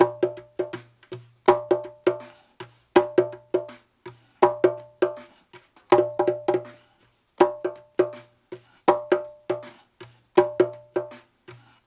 81-BONGO3.wav